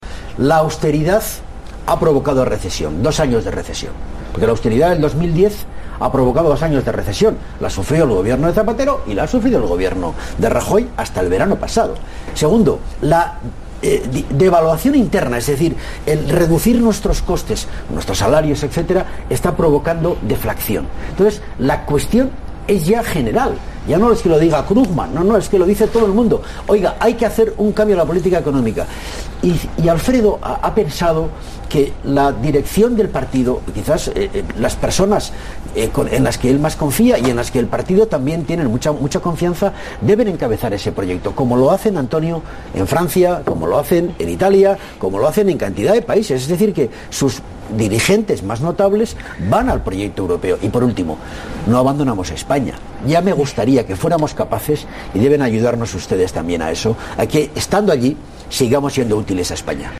Fragmento de la entrevista a Ramón Jáuregui en los Desayunos de TVE 17/03/2014